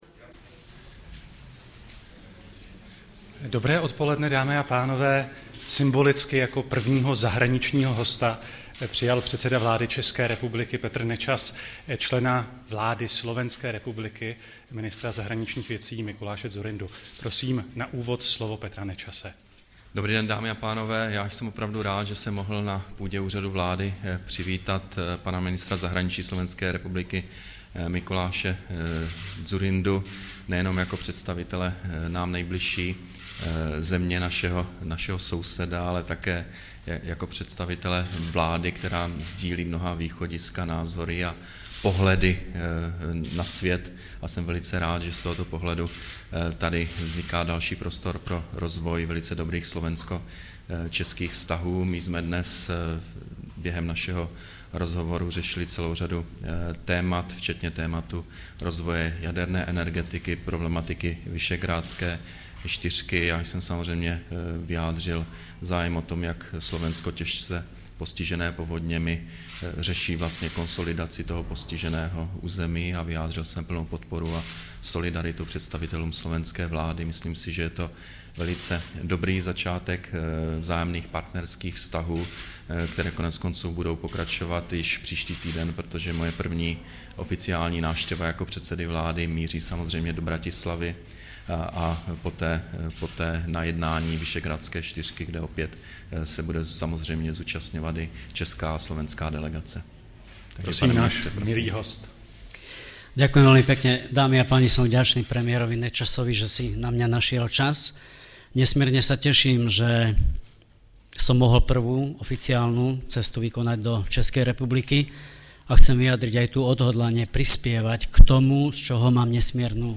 Tiskový brífink po setkání premiéra Petra Nečase se slovenským ministrem zahraničí Mikulášem Dzurindou, 15.7.2010